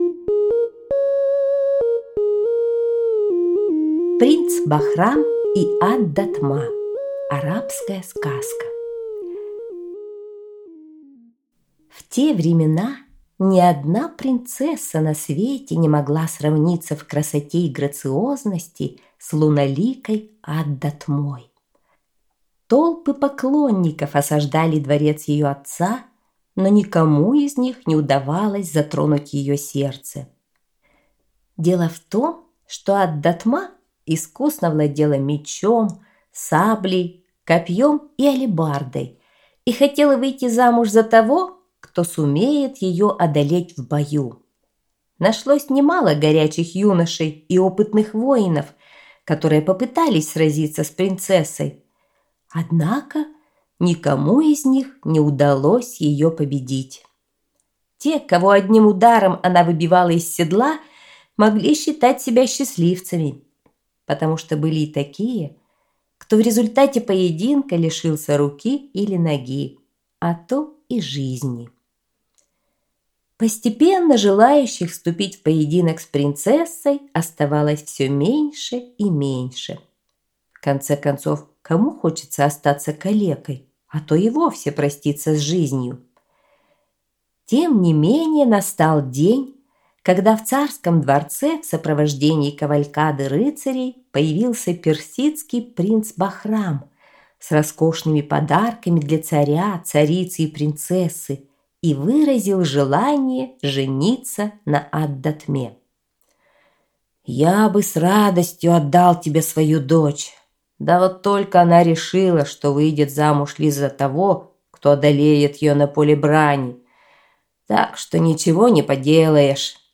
Принц Бахрам и Ад-Датма – арабская аудиосказка